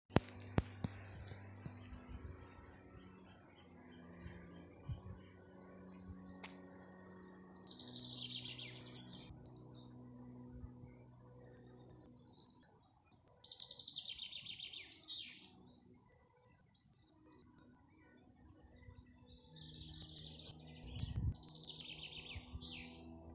Chaffinch, Fringilla coelebs
Ziņotāja saglabāts vietas nosaukumsAlūksnes nov. Opekalns
StatusSinging male in breeding season